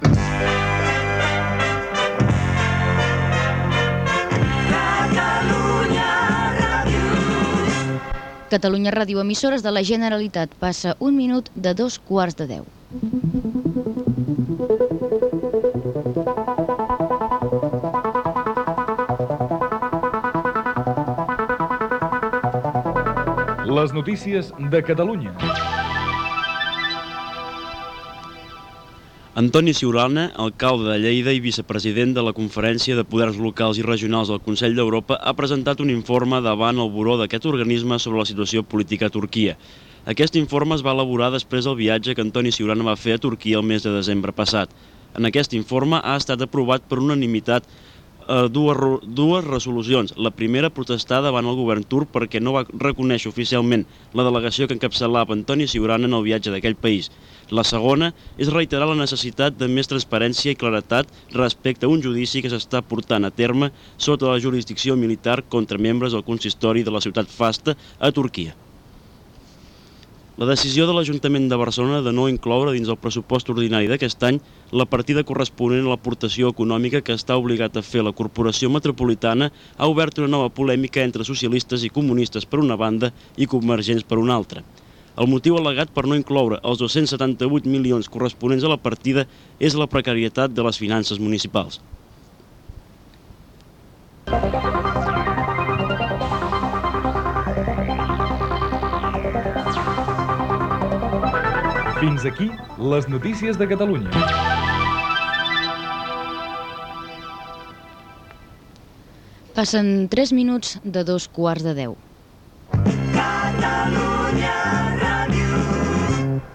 Hora, indicatiu de l'emissora
Informatiu